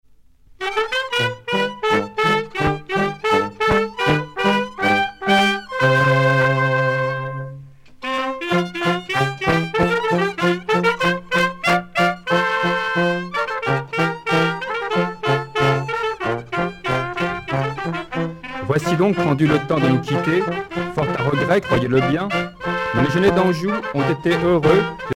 danse : polka
groupe folklorique